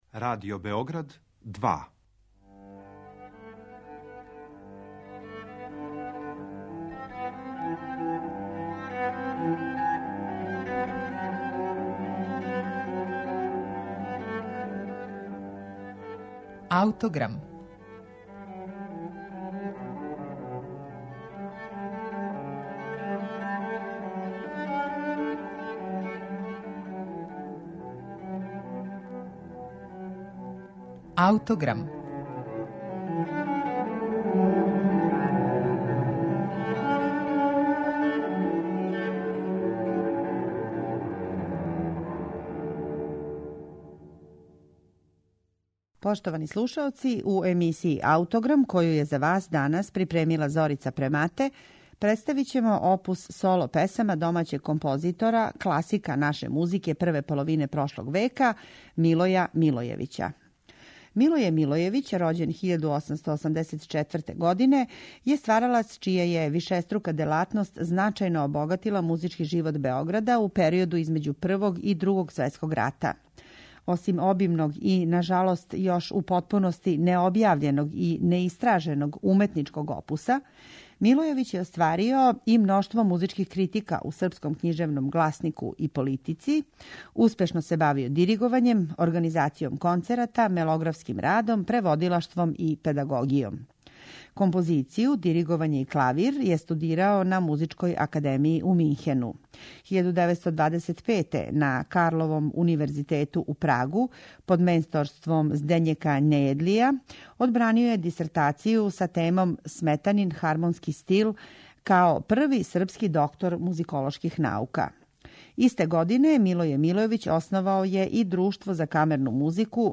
Овога пута посветили смо је соло песмама Милоја Милојевића, једног од градитеља савременог српског музичког израза између два рата.
слушаћете архивске снимке
пијанисте